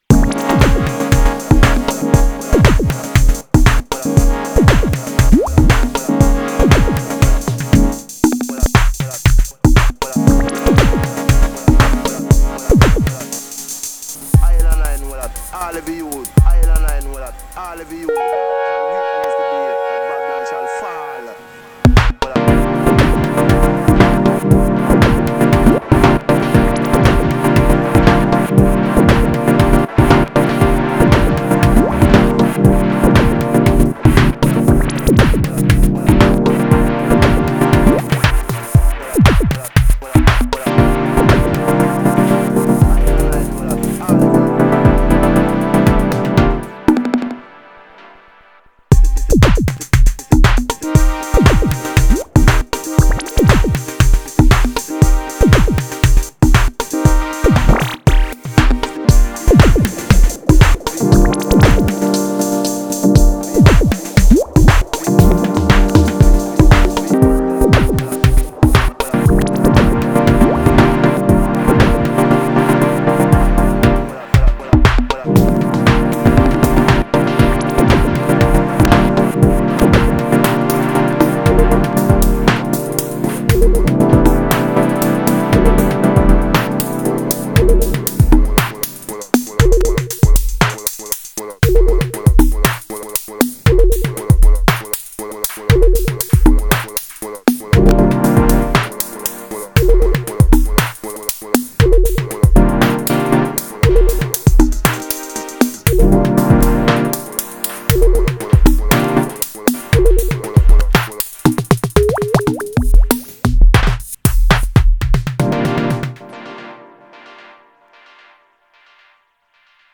I did this cause I was jealous, maybe even envious, of that punchy 606 sound.
Samples: 606 from Mars, Rockers vocal, my Minilogue.